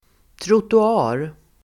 Ladda ner uttalet
trottoar substantiv, pavement, (sidewalk [US])Uttal: [troto'a:r] Böjningar: trottoaren, trottoarerSynonymer: gångbanaDefinition: gångbana bredvid körbana